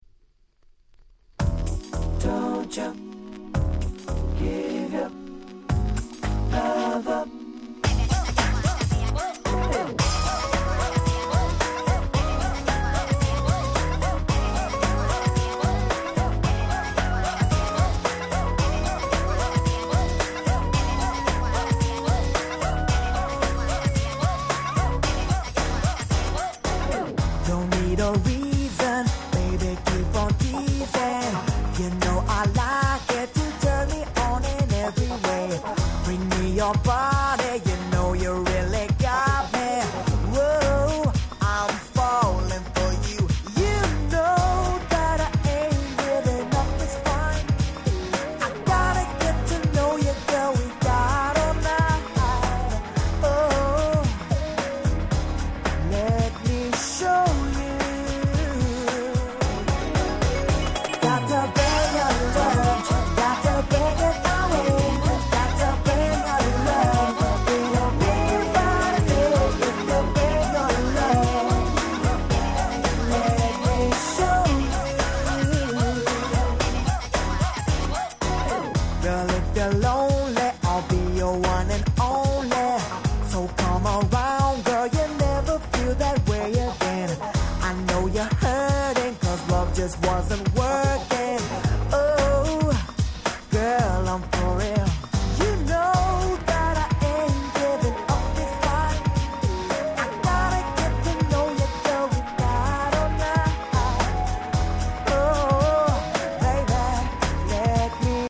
White Press Only Nice R&B Complilation !!!!!